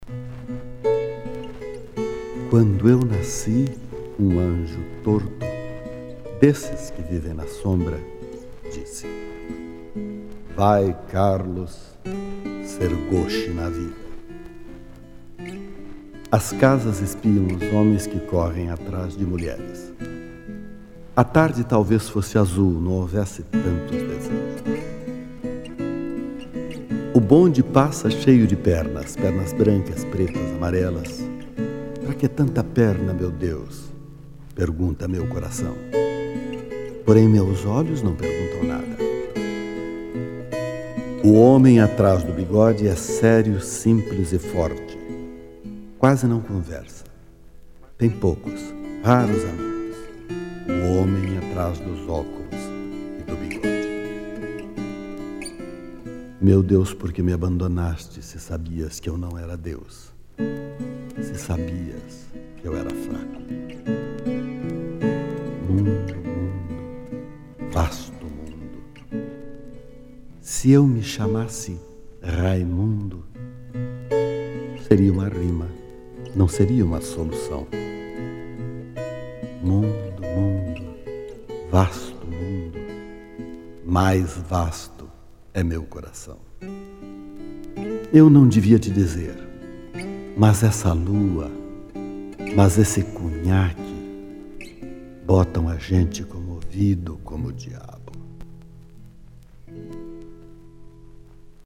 Carlos Drummond de Andrade interpretado por Lima Duarte - Músicas: Roberto Corrêa